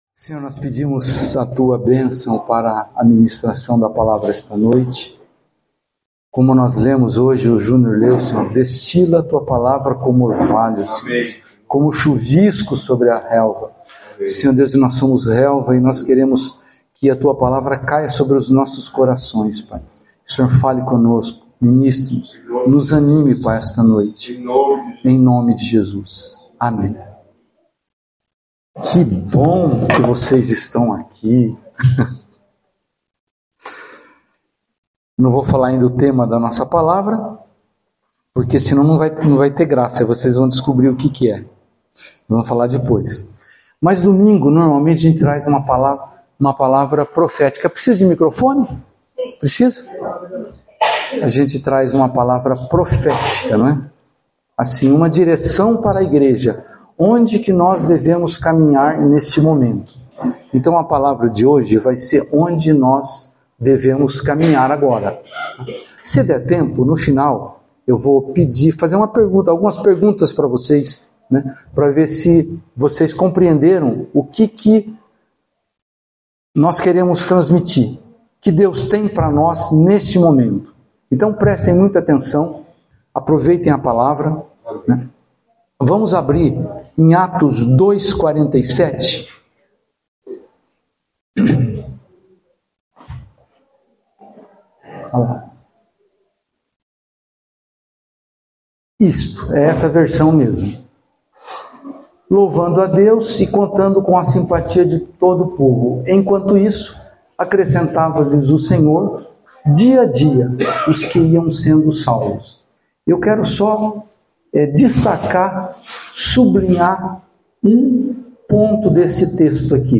Palavras ministradas Jogar bem ou fazer gol?